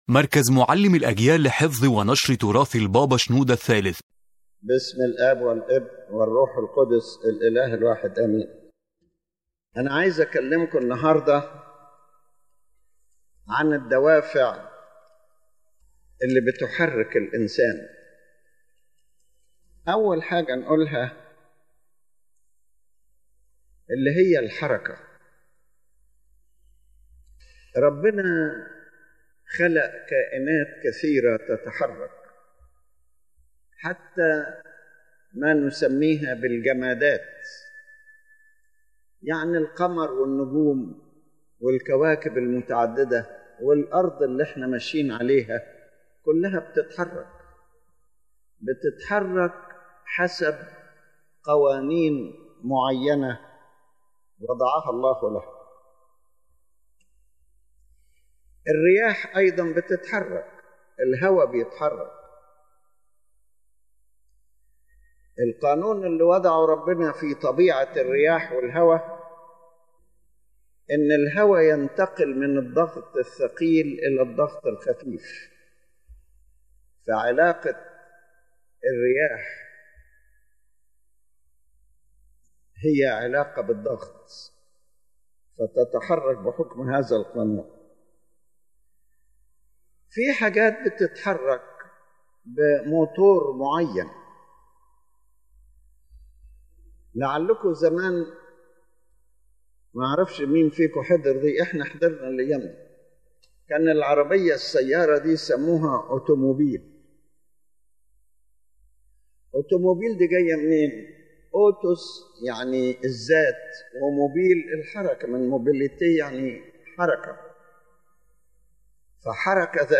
The Main Idea of the Lecture: His Holiness Pope Shenouda III speaks about the forces and motives that drive human behavior, explaining that a human being is not driven by a single factor, but by a complex set of influences such as the mind, emotions, desires, benefit, conscience, habits, and nature.